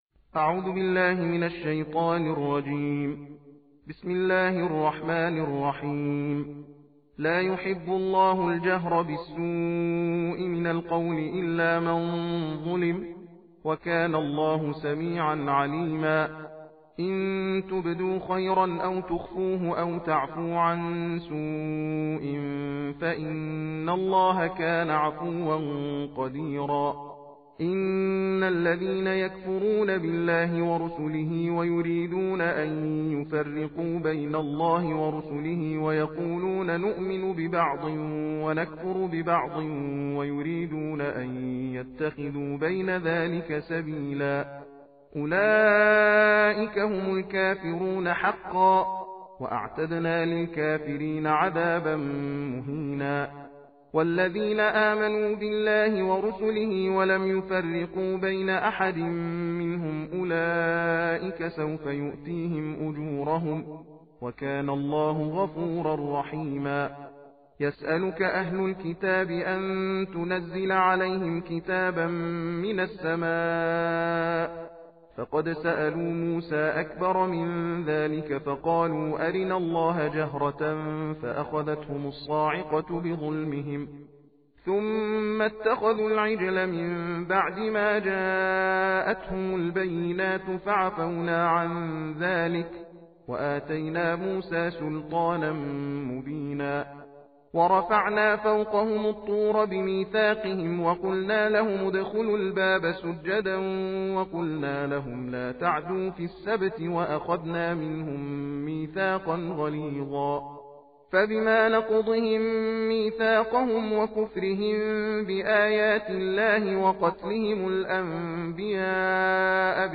تندخوانی و تحدیر جزء ششم قرآن کریم را با یکدیگر زمزمه می کنیم.